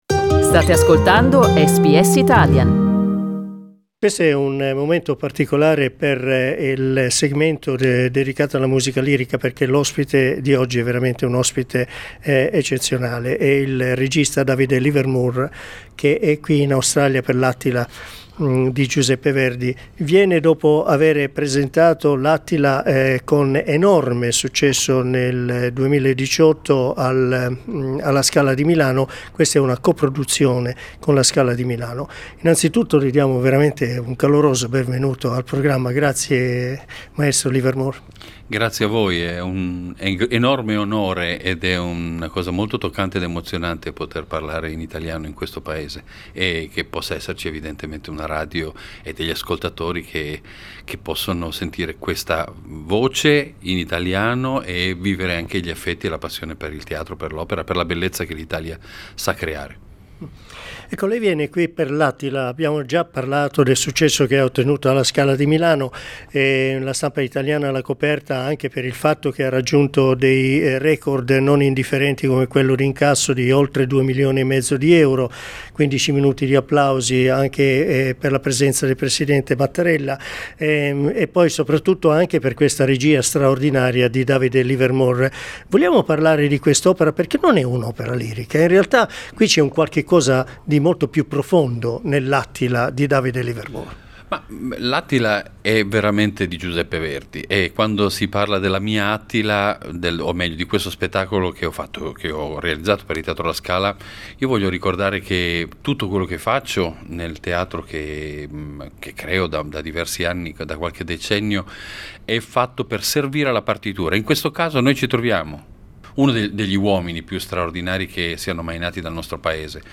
In this interview with SBS Italian, Davide Livermore says that the current tense political climate inspired him setting the opera in 1943 during the Civil War in Italy.